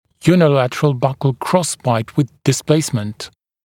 [juːnɪ’lætərəl ˈbʌkl ‘krɔsbaɪt wɪð dɪs’pleɪsmənt][йу:ни’лэтэрэл ˈбакл ‘кросбайт уиз дис’плэйсмэнт]односторонний боковой перекрестный прикус со смещением нижней челюсти